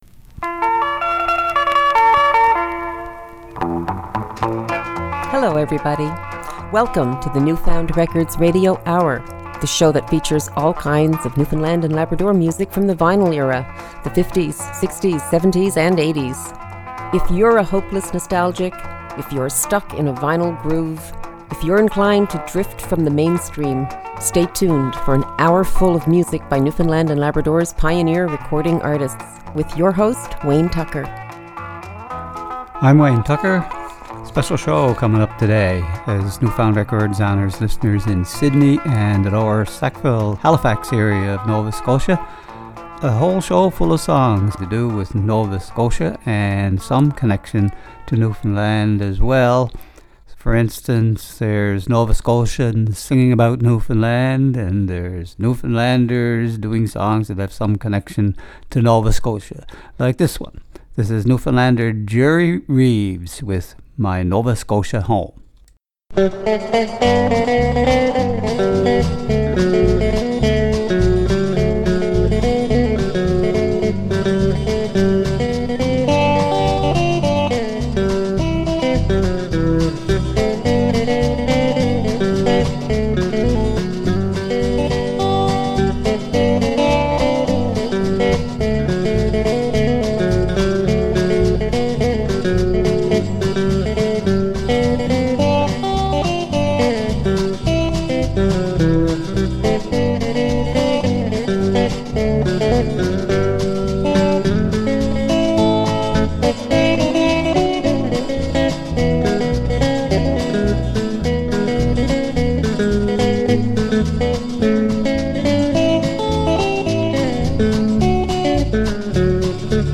Recorded at the CHMR Studios, Memorial University, St. John's, NL.